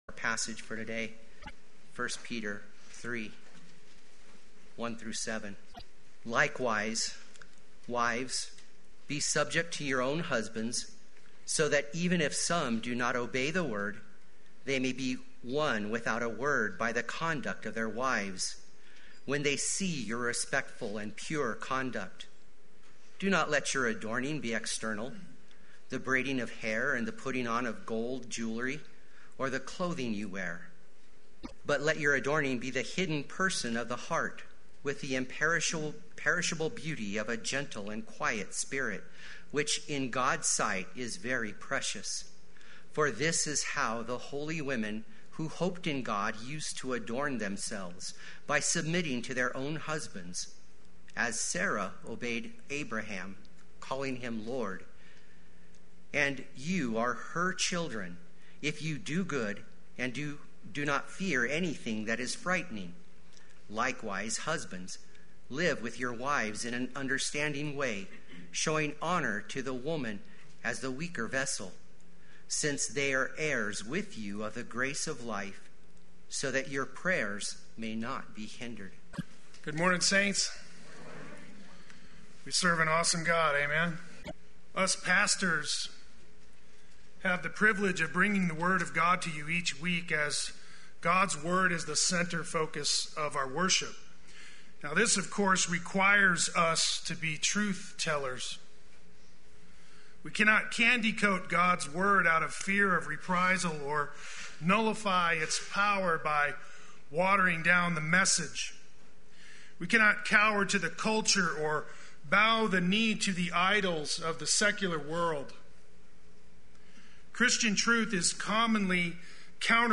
Play Sermon Get HCF Teaching Automatically.
The Cross-Centered Marriage Sunday Worship